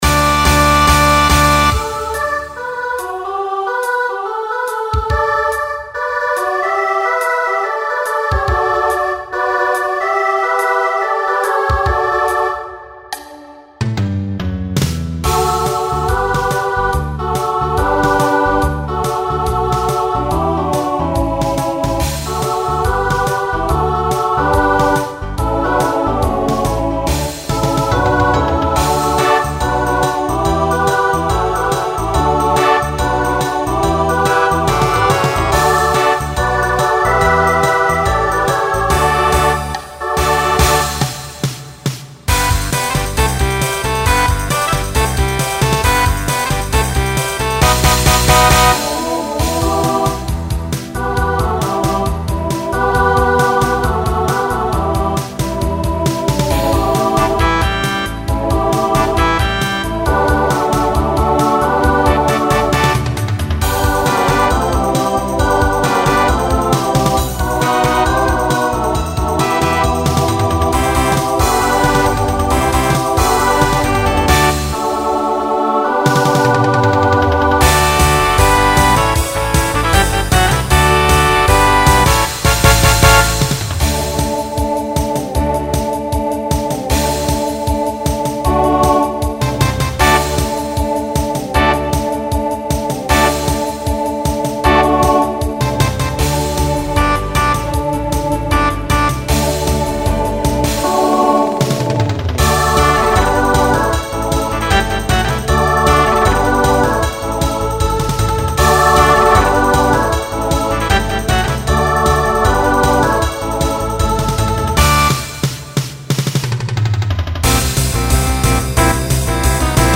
Genre Pop/Dance , Rock
Voicing SATB